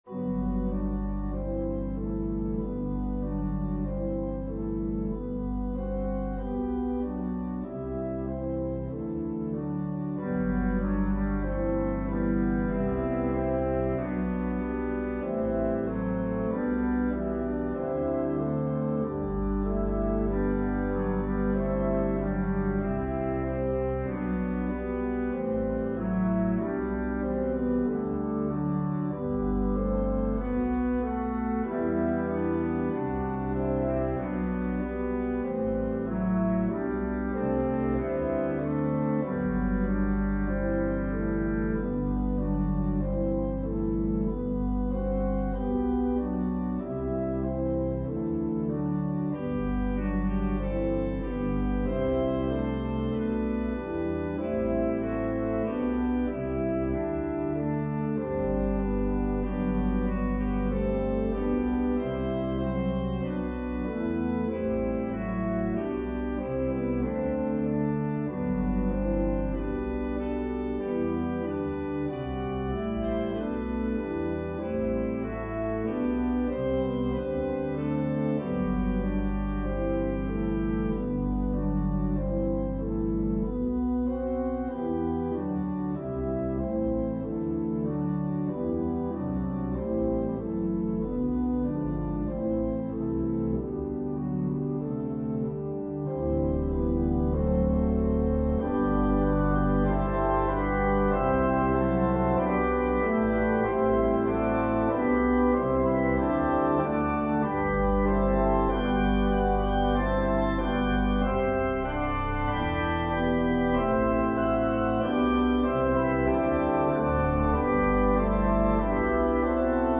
An organ solo arrangement
Voicing/Instrumentation: Organ/Organ Accompaniment